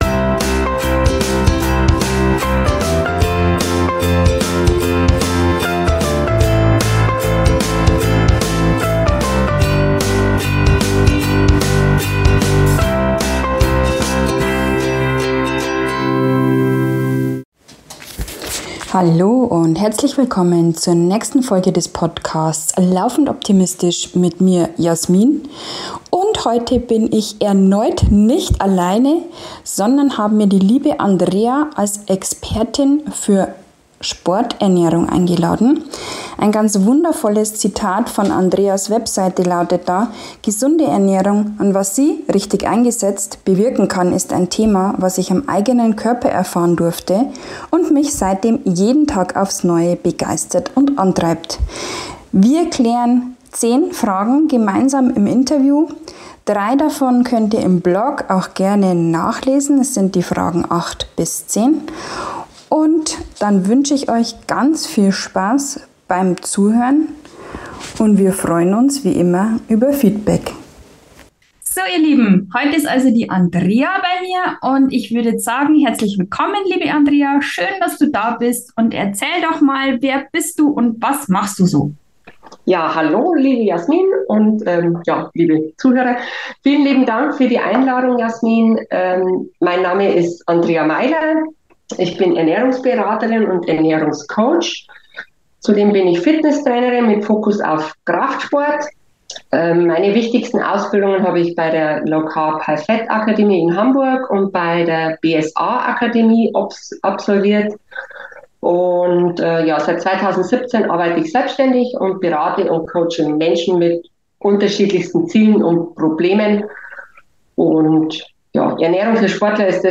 Beschreibung vor 1 Jahr Wow bereits die dritte Interview Folge hintereinander.